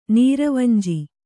♪ nīravanji